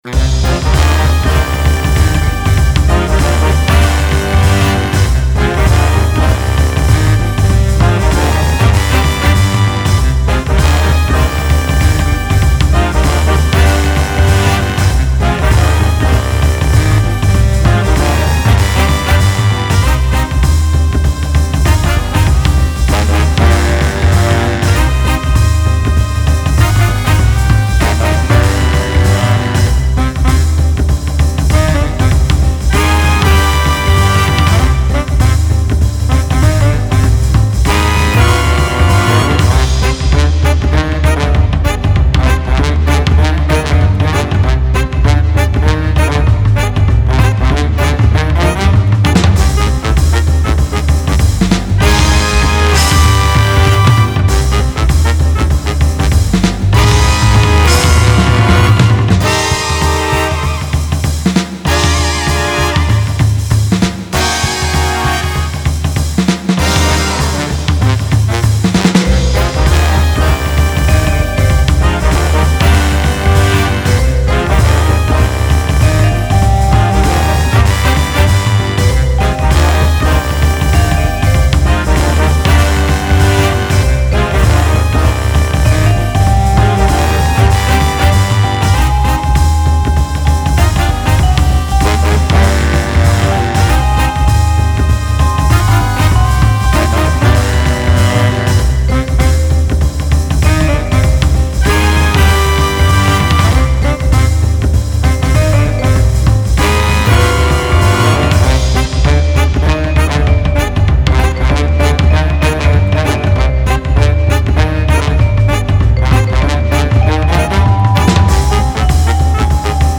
Style Style Jazz, Oldies
Mood Mood Cool, Intense
Featured Featured Brass, Drums, Piano +1 more
BPM BPM 195